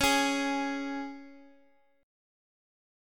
Db5 chord
Db-5th-Db-x,x,x,x,2,4-1-down-Guitar-Standard-1.m4a